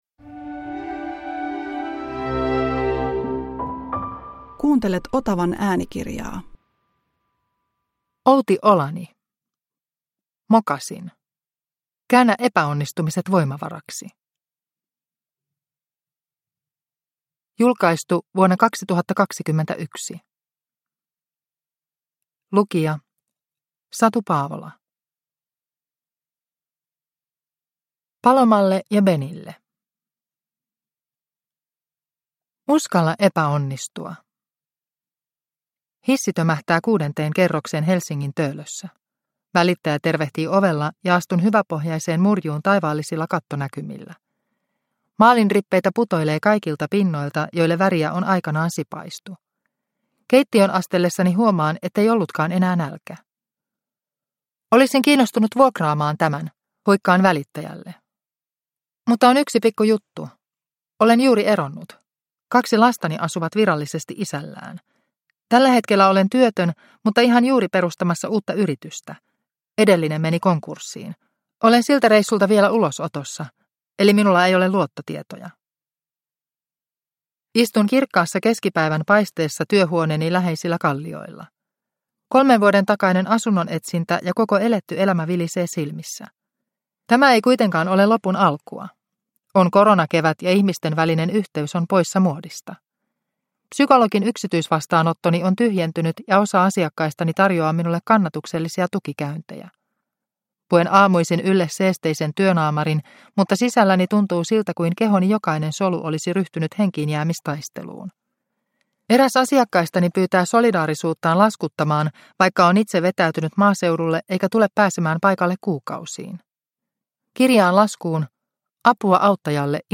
Mokasin – Ljudbok – Laddas ner